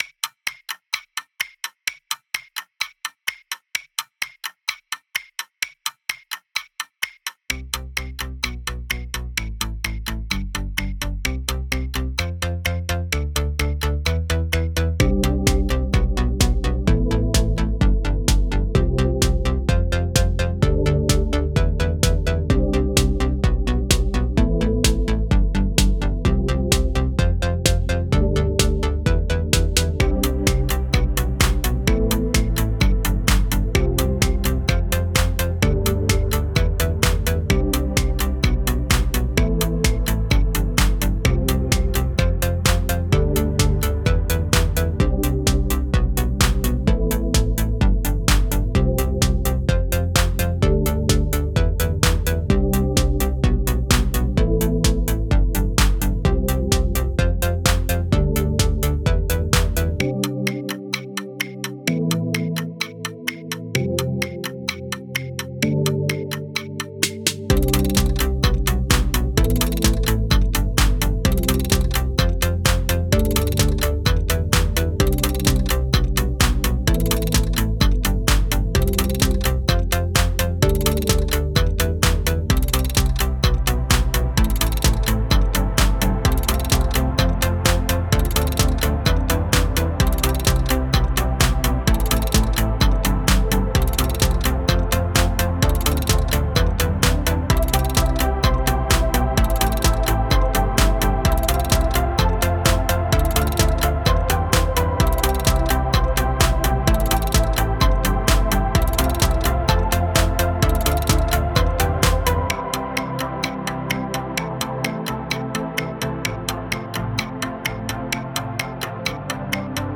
If you watched the Arma Gear Don video above, you might have noticed that this song is played during the background of each level. I revisited the old Clockwork to remix and master it and bring it up to my current standard of quality, specifically for this project.